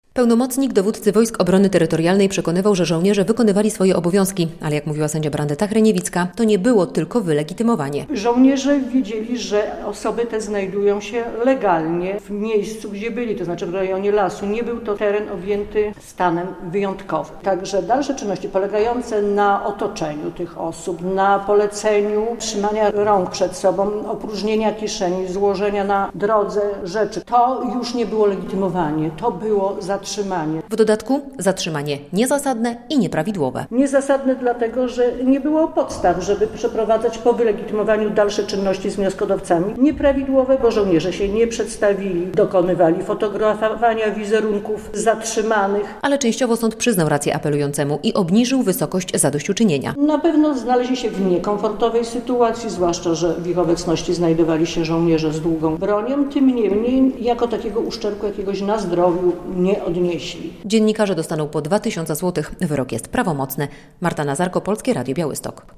Troje dziennikarzy zatrzymanych przy granicy dostanie zadośćuczynienie - relacja